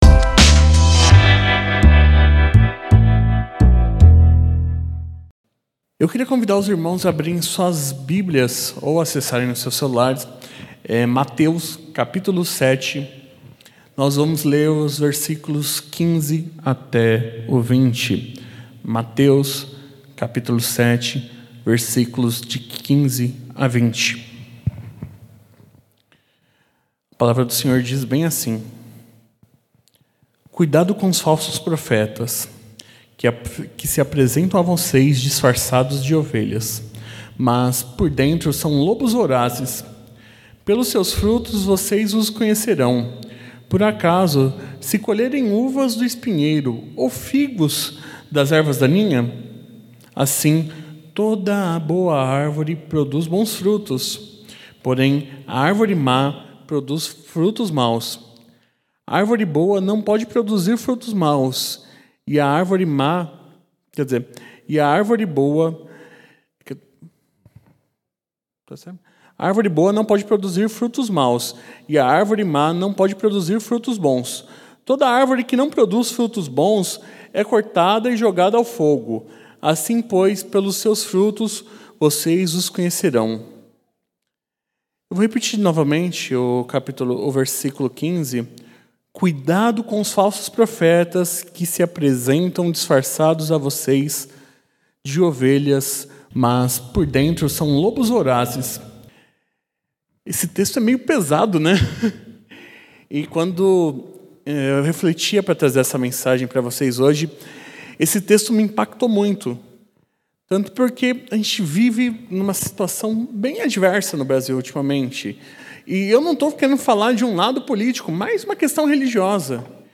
nsagem realizada no Culto de Reflexão de Oração.